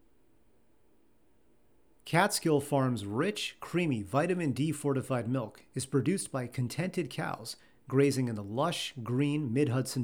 I am recording from a very quiet room, and I have had a few ACX offers but I am scared to proceed with a full-length recording if I can’t get this right.
Your noise is not THAT bad and the mastering page has several suggestions for dealing with noise.
It sounds like you’re recording in your office with bare walls and floor, or kitchen, or bathroom. Echo city.
There is quite a bit of noise behind you.